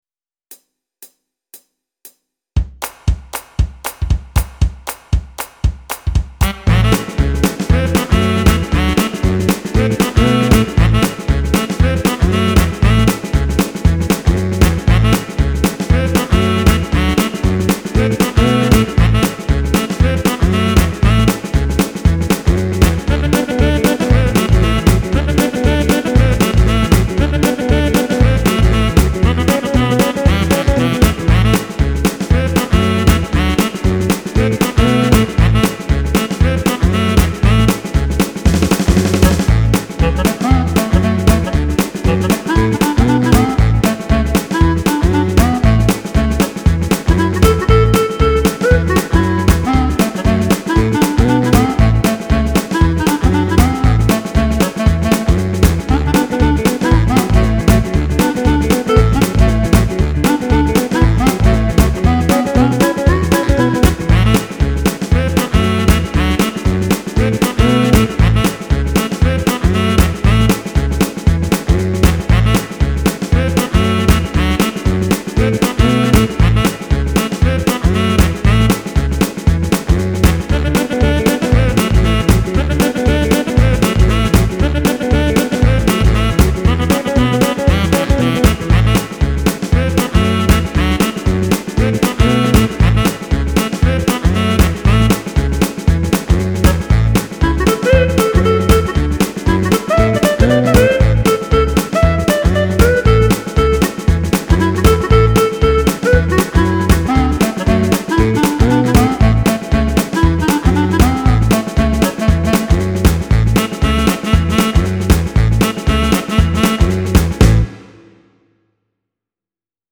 And… they’ll have a clarinet solo.